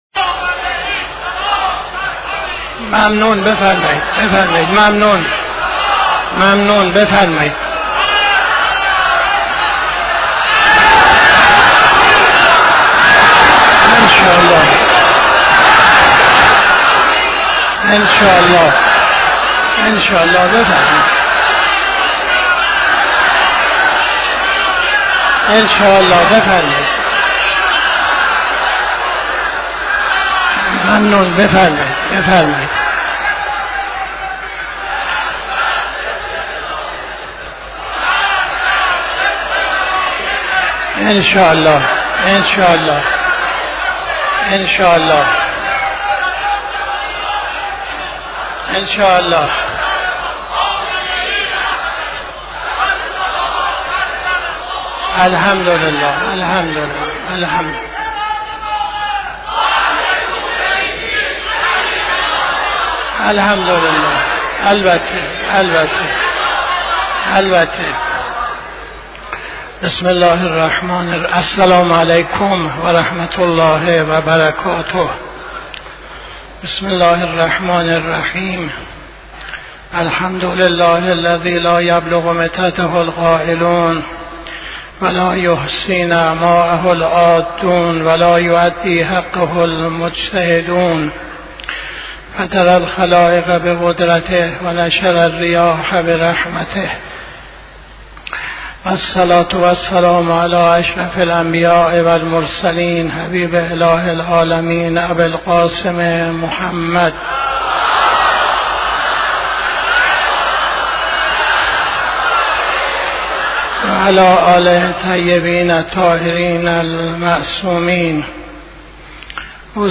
خطبه اول نماز جمعه 29-01-82